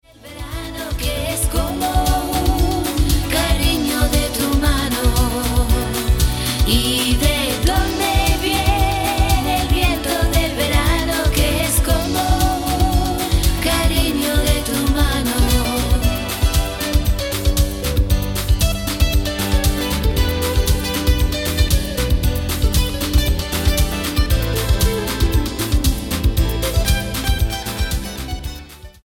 STYLE: Latin